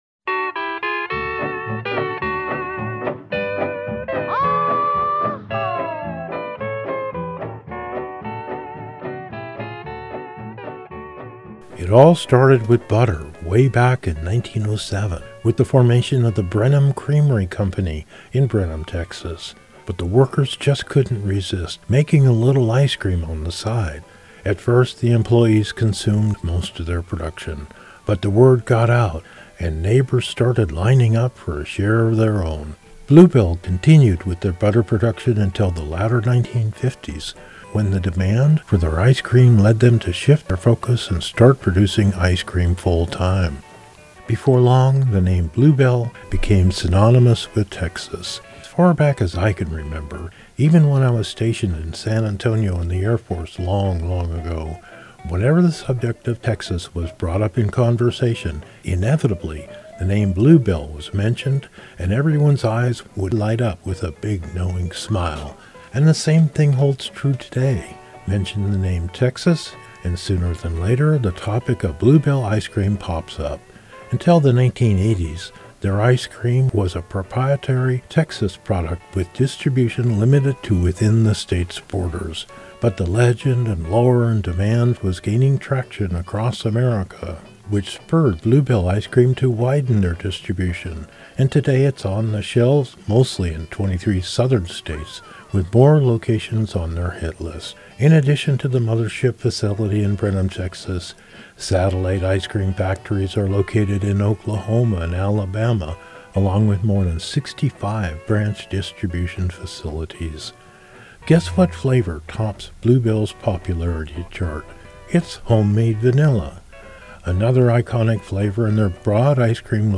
reports from Brenham, Texas at the Blue Bell Ice Cream factory